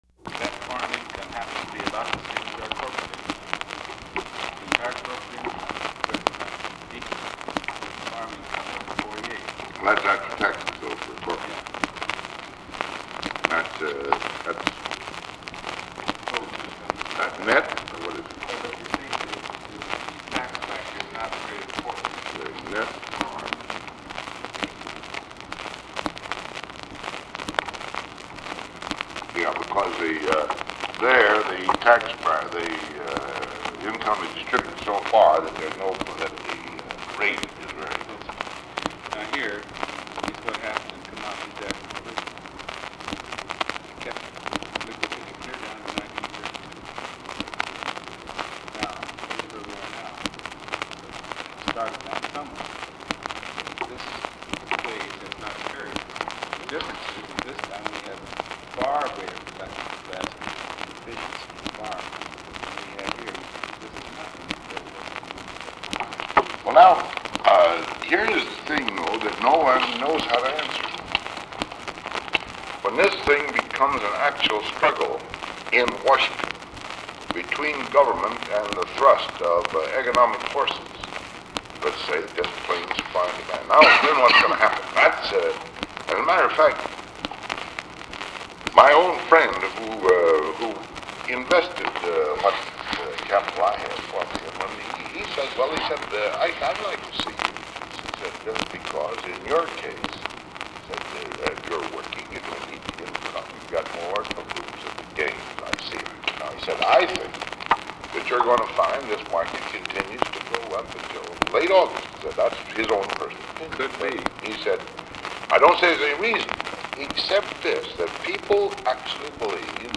The guest on this recording sounds very like the individual on EL-T-IBM-272. Eisenhower and his guest are discussing net farm income and corporate income.
Meeting with Unidentified Man (cont)—ca. 1950